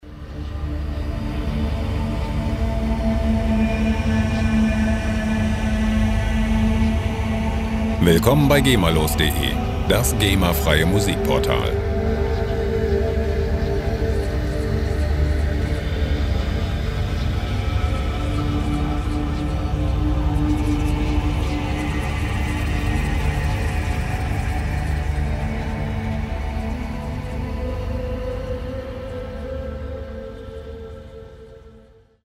Ambient Loops gemafrei
Musikstil: Ambient
Tempo: 113 bpm